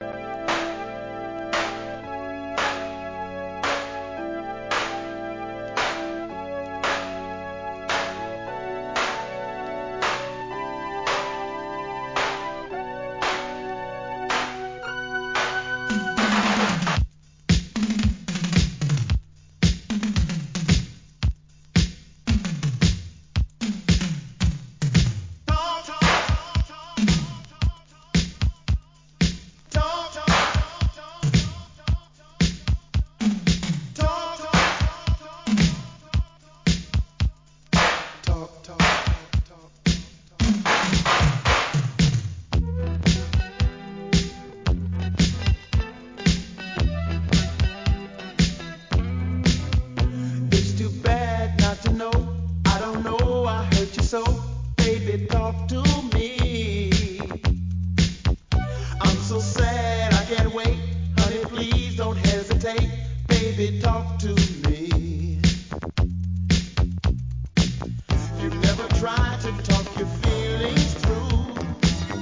SOUL/FUNK/etc...
Club Mix